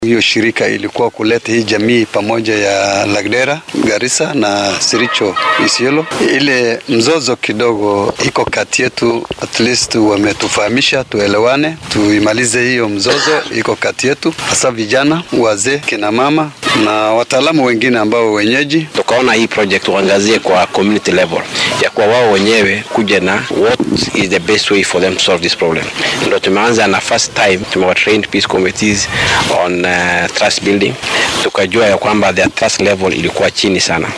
Arrintaan waxaa ka hadlay waxgaradka laba dowlad deegaan oo wada hadallada u socdaan.